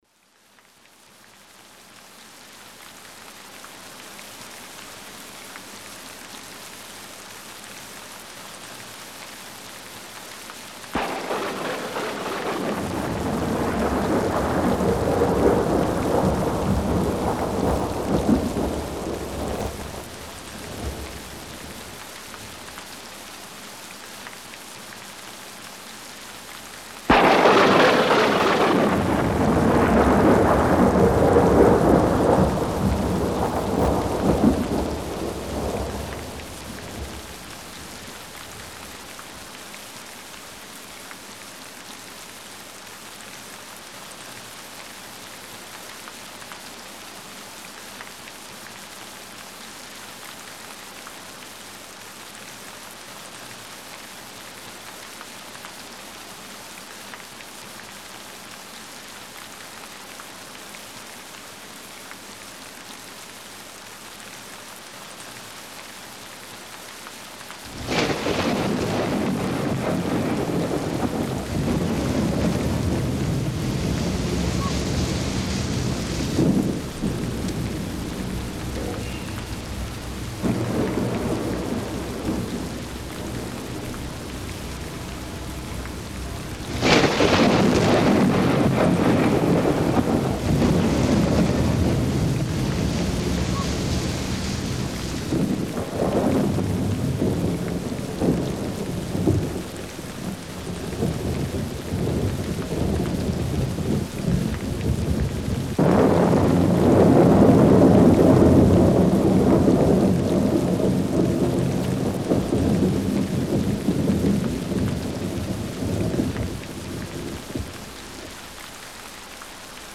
3D spatial surround sound "Rainy day"
3D Spatial Sounds